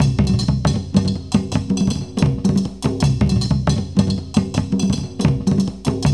Index of /90_sSampleCDs/Zero G - Ethnic/Partition A/DRUMS+BELLS
DRUMBELLS4-L.wav